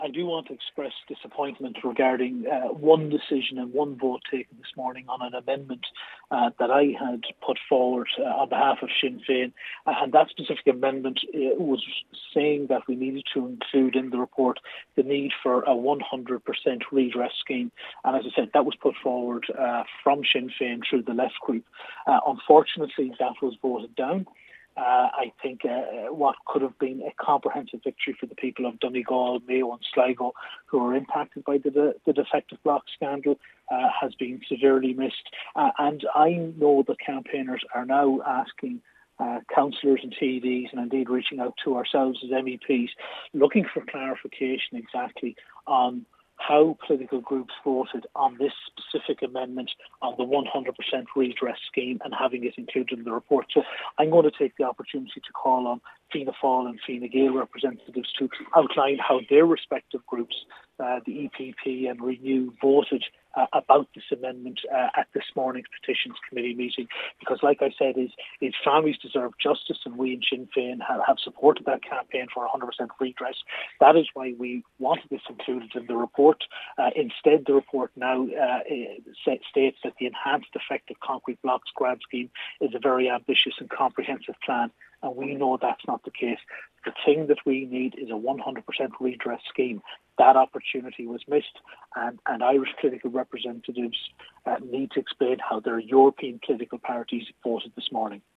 Midlands North West MEP Chris MacManus believes it was a missed opportunity: